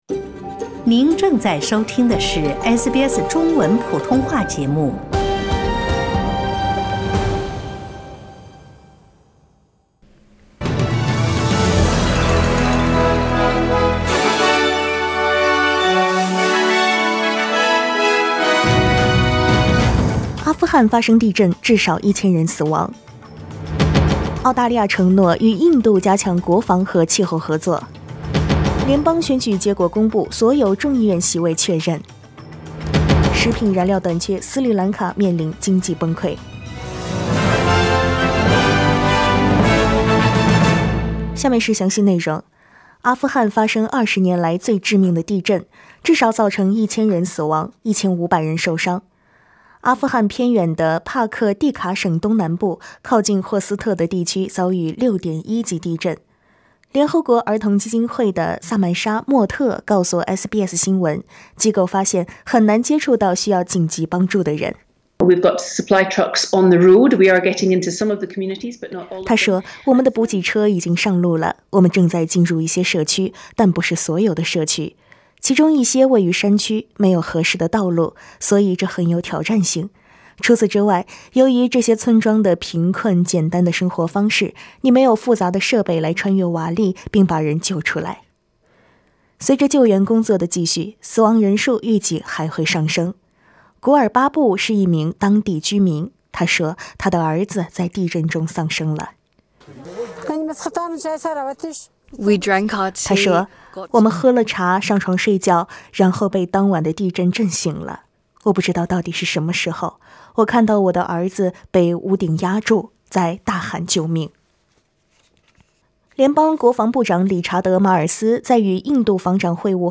SBS早新闻（6月23日）